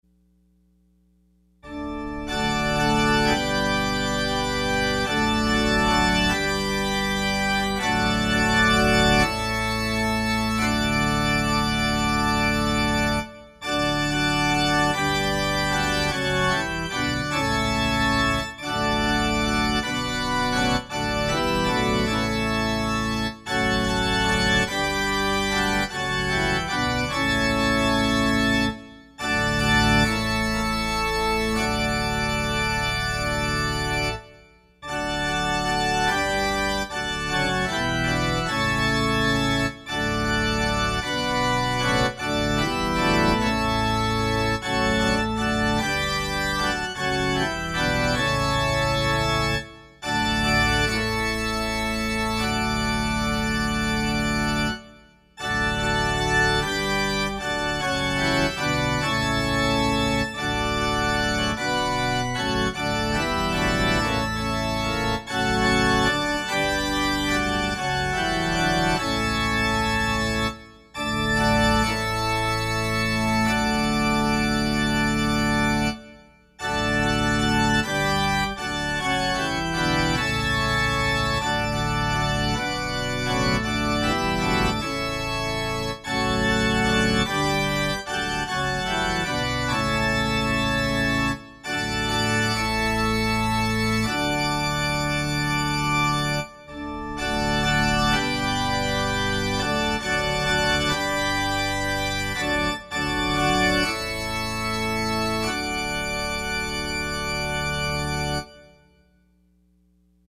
Opening Hymn: The Strife is o’er, the battle done